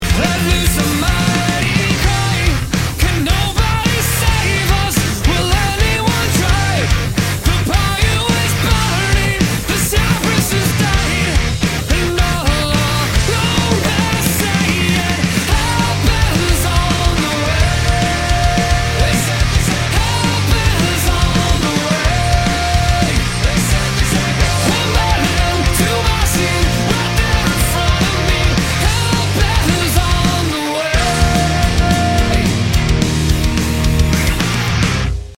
Главная » Файлы » Рок